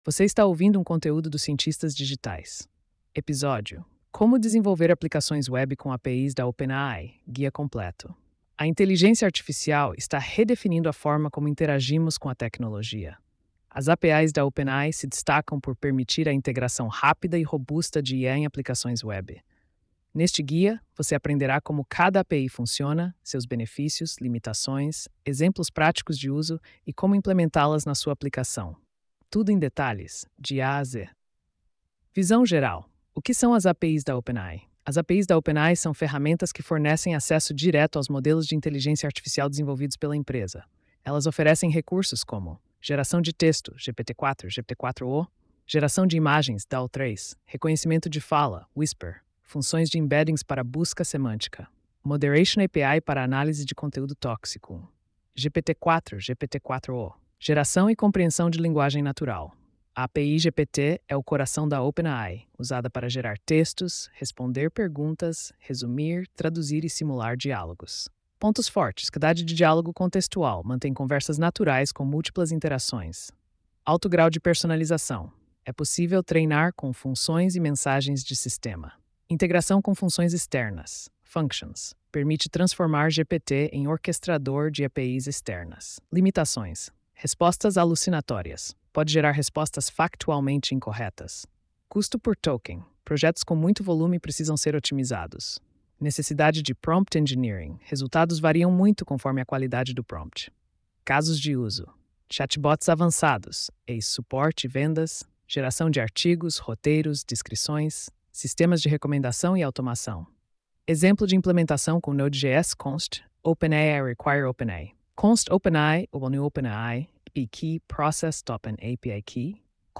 post-3115-tts.mp3